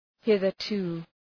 Προφορά
{‘hıðər,tu:}